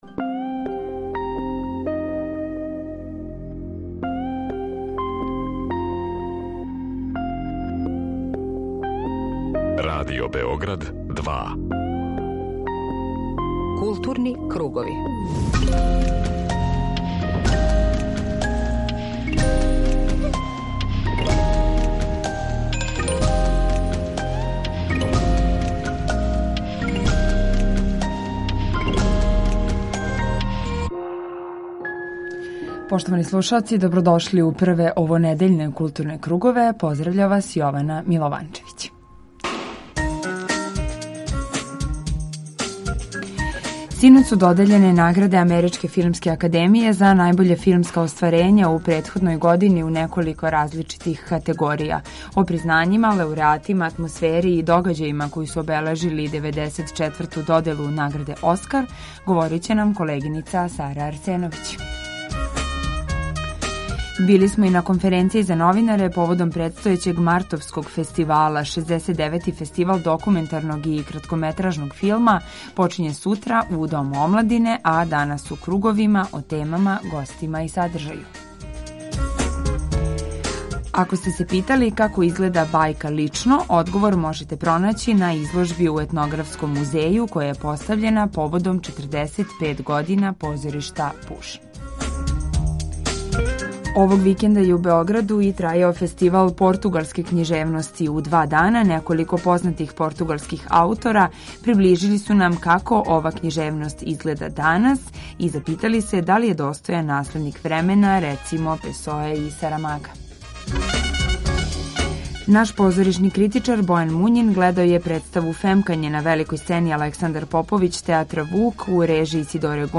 Централна културно-уметничка емисија Радио Београда 2.